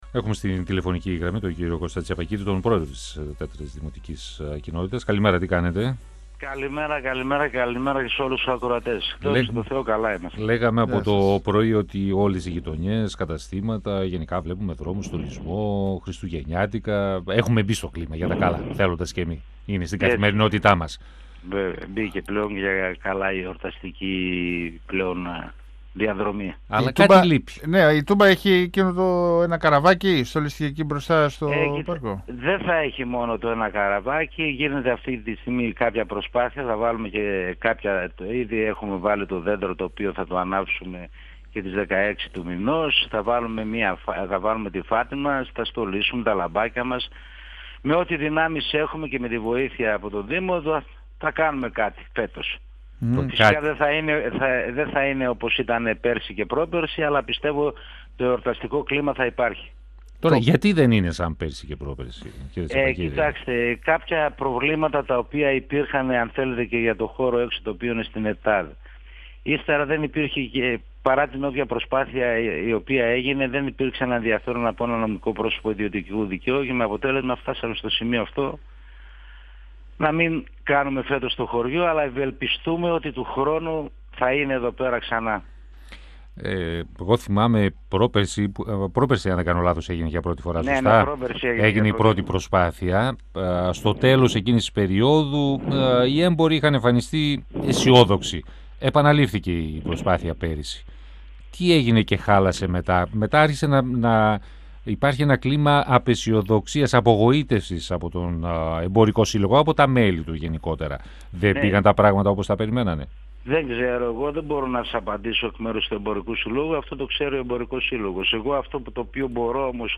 O πρόεδρος της Δ’ Δημοτικής Κοινότητας της Θεσσαλονίκης Κώστας Τσιαπακίδης,  στον 102FM του Ρ.Σ.Μ. της ΕΡΤ3